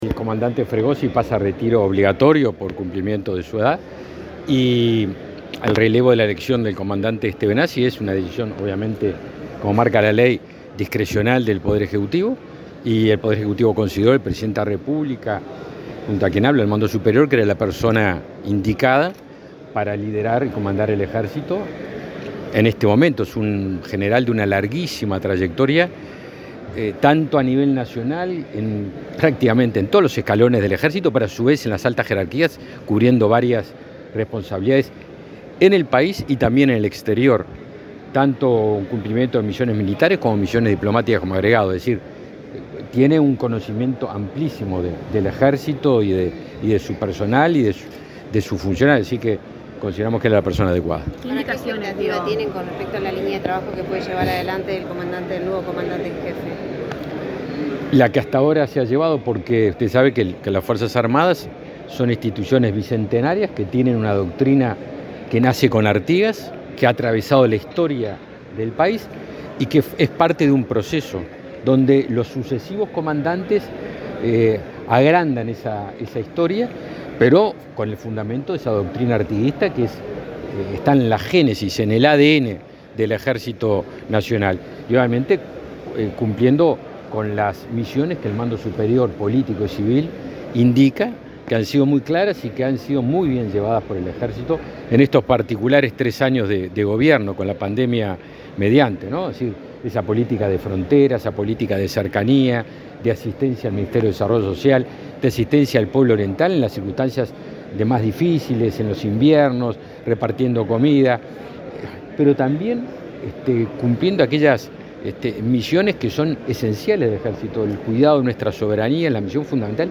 Declaraciones del ministro de Defensa, Javier García
El ministro de Defensa, Javier García, dialogó con la prensa luego de participar en el acto de asunción del nuevo comandante en jefe del Ejército,